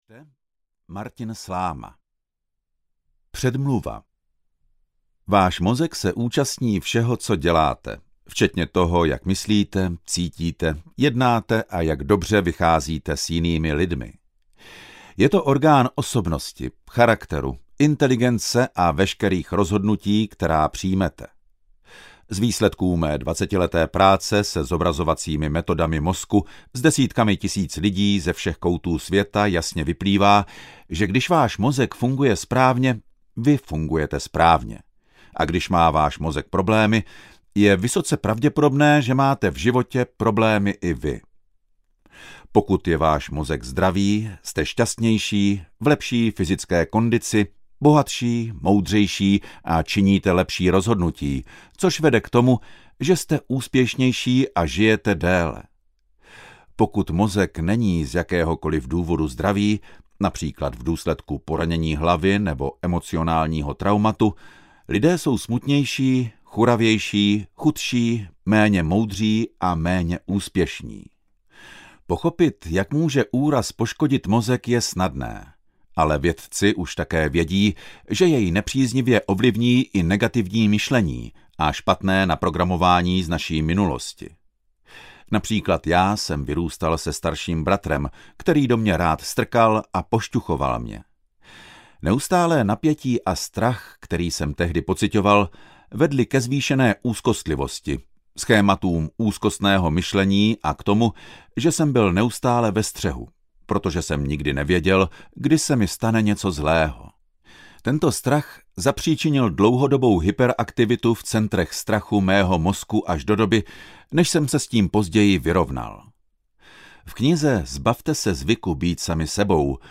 Zbavte se zvyku být sami sebou audiokniha
Ukázka z knihy